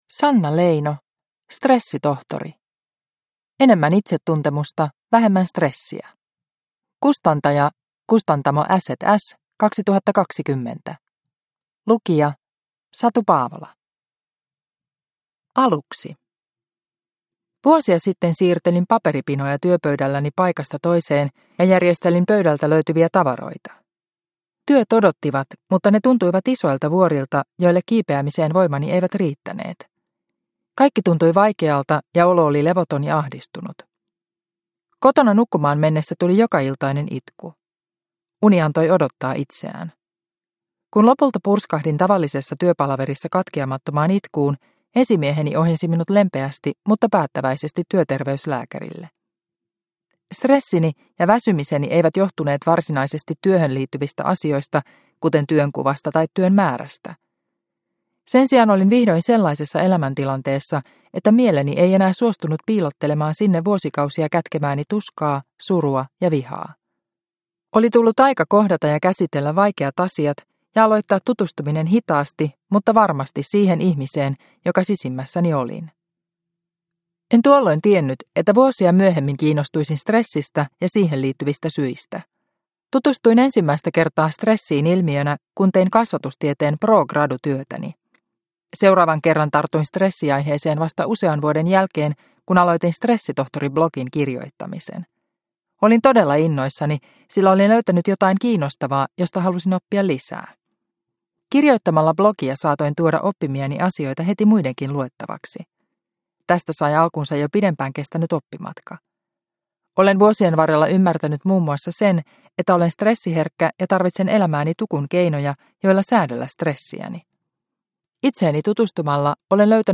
Stressitohtori – Ljudbok – Laddas ner